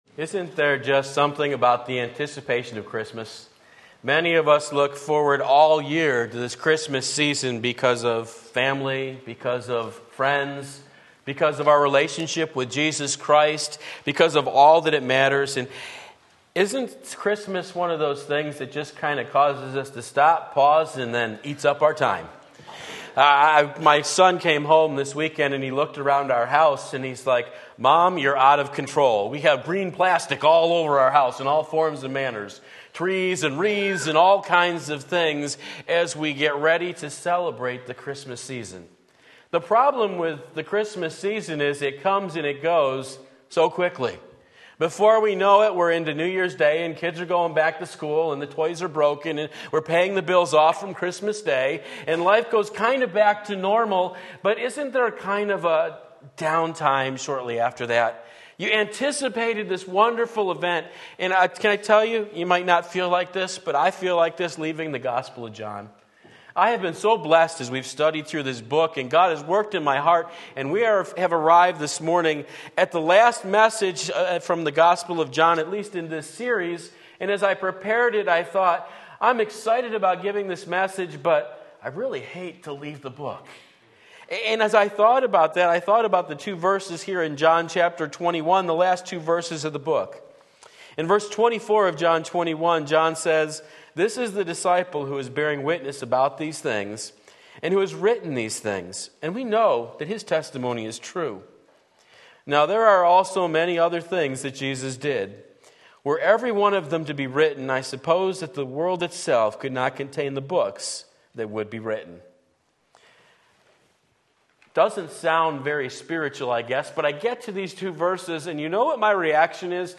Sermon Link
John 21:24-25 Sunday Morning Service, December 3, 2017 Believe and Live!